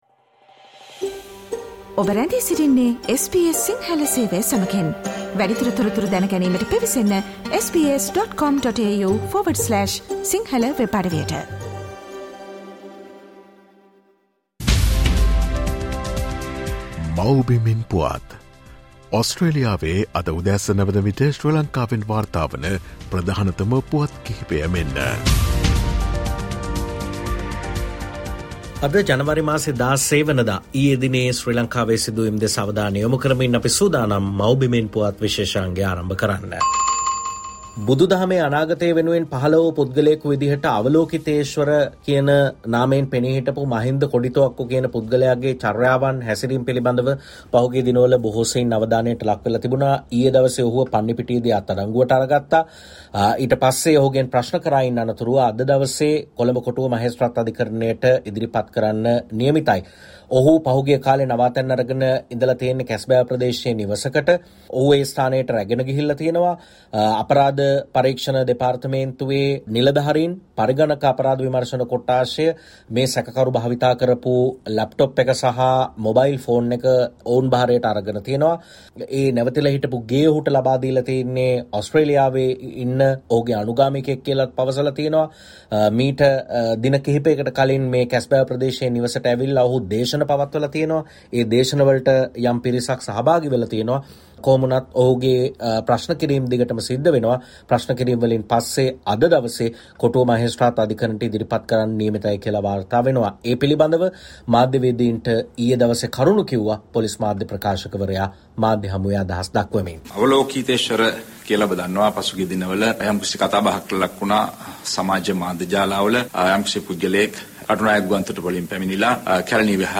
SBS Sinhala featuring the latest news reported from Sri Lanka - Mawbimen Puwath